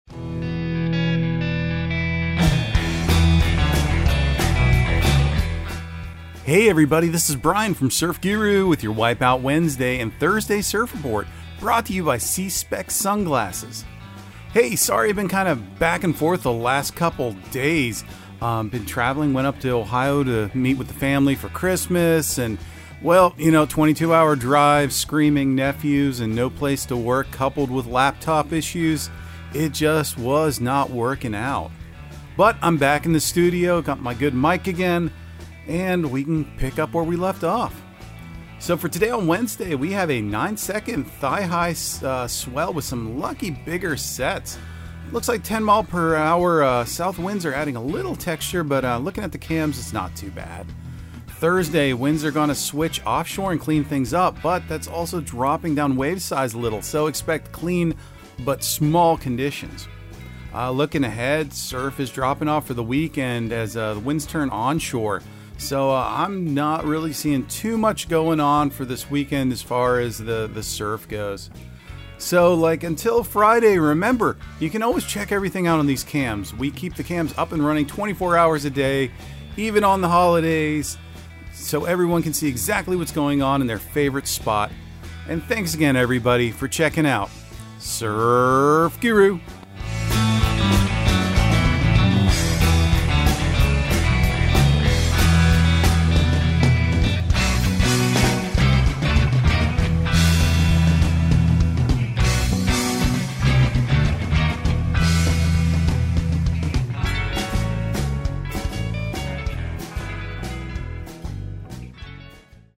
Surf Guru Surf Report and Forecast 01/04/2023 Audio surf report and surf forecast on January 04 for Central Florida and the Southeast.